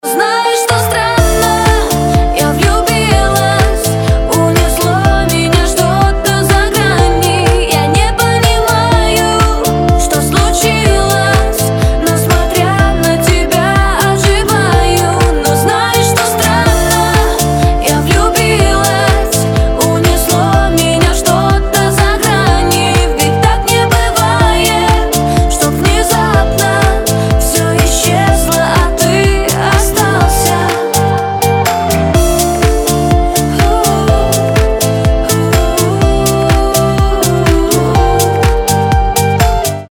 • Качество: 320, Stereo
поп
женский вокал
dance